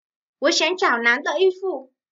Wǒ xiǎng zhǎo nán de yīfu.
Ủa xéng trảo nán tợ y phụ.